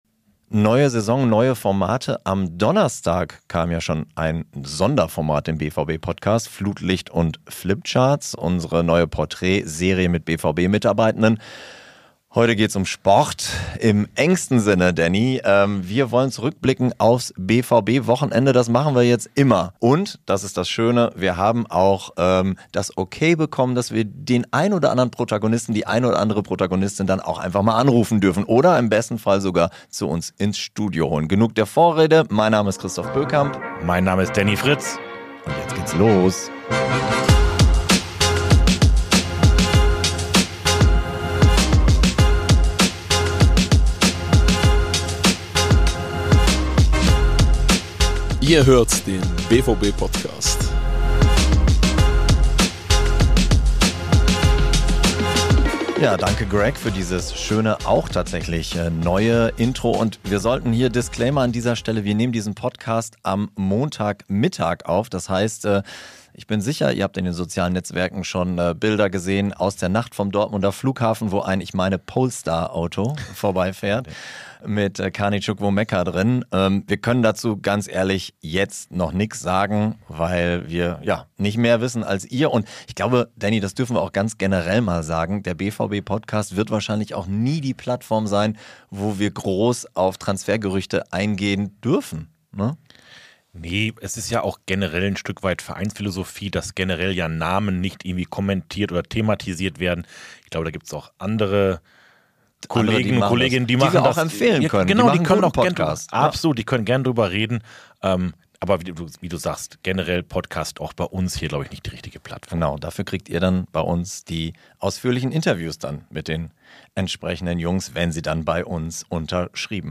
Dafür telefonieren die Hosts